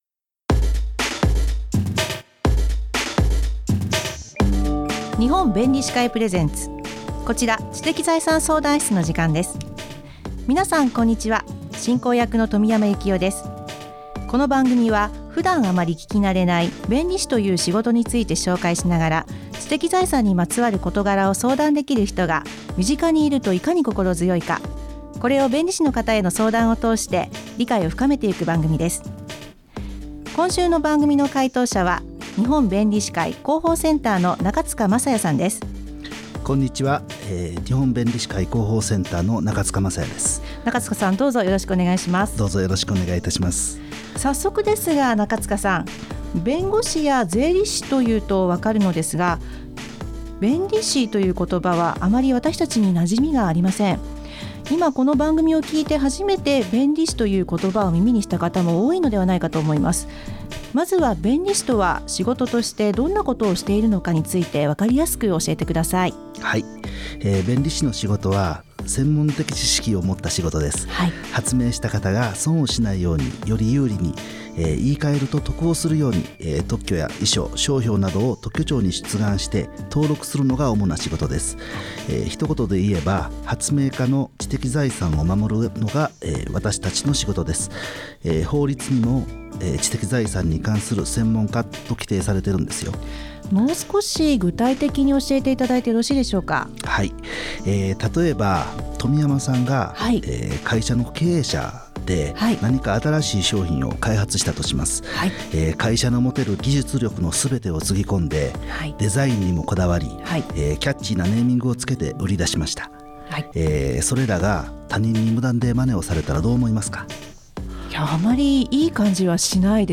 日本弁理士会提供ラジオ番組にて放送しました。
知的財産にまつわる質問に弁理士が答えます！